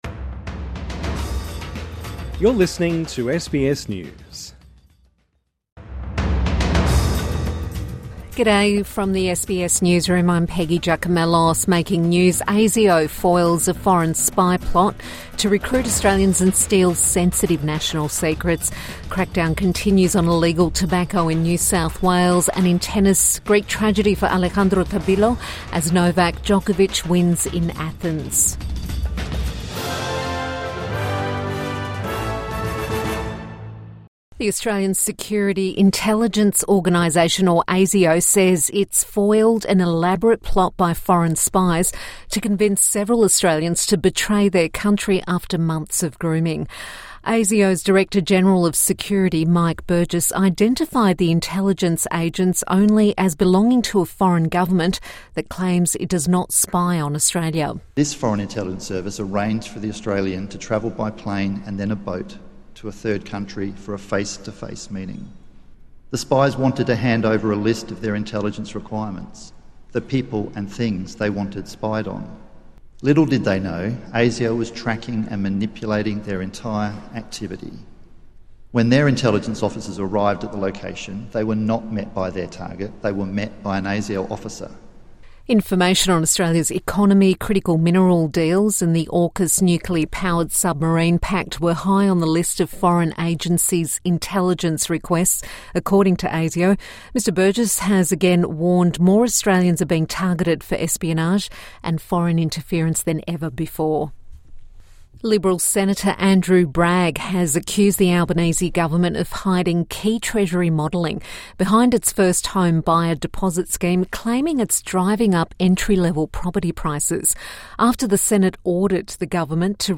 ASIO foils foreign spy plot to recruit Australians | Midday News Bulletin 5 November 2025